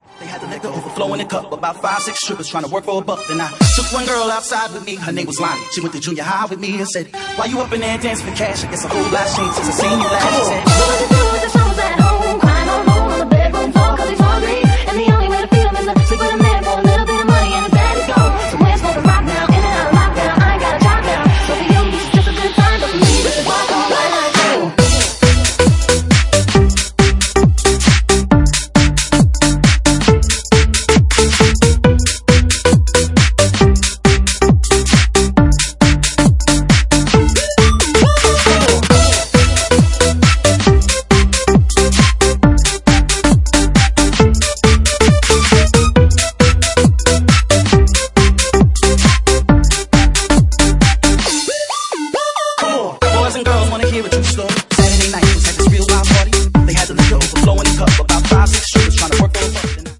Genre:Bassline House
- Bassline House at 137 bpm